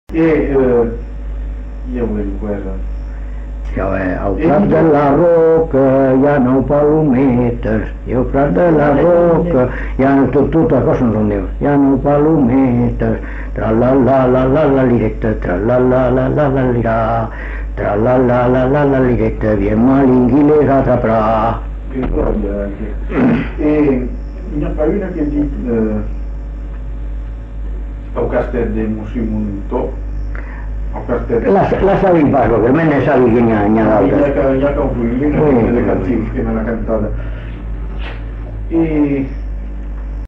Aire culturelle : Bazadais
Lieu : Bazas
Genre : chant
Effectif : 1
Type de voix : voix d'homme
Production du son : chanté
Danse : rondeau